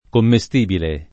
[ komme S t & bile ]